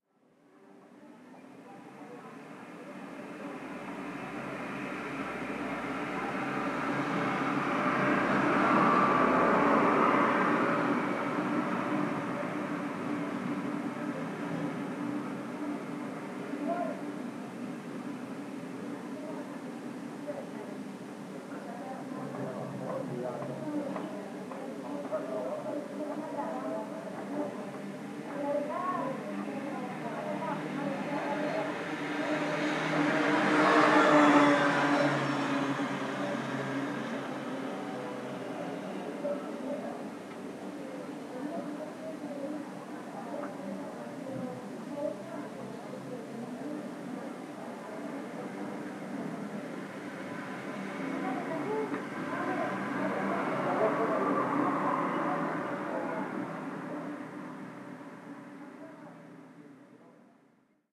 Ambiente interior de una habitación ruidosa por el tráfico
tráfico
bullicio
ruido
Sonidos: Gente
Sonidos: Transportes